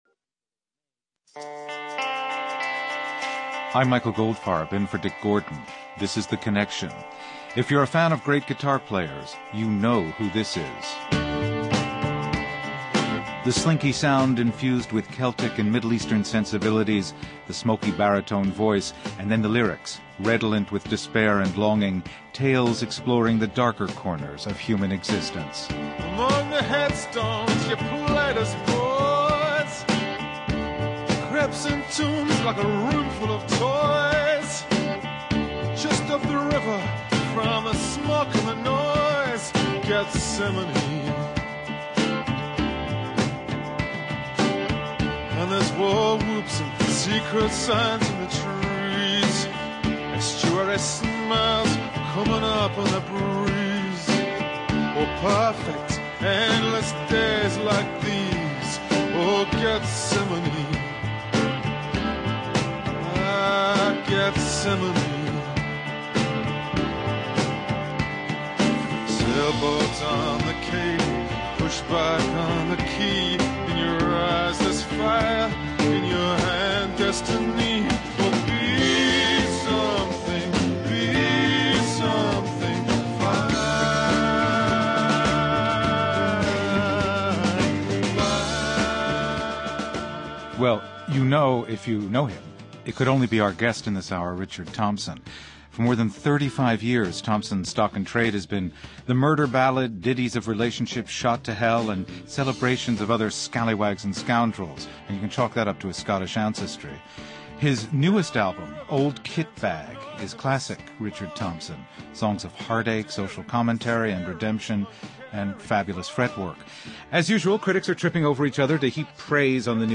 Guests: Richard Thompson, guitarist, singer, and songwriter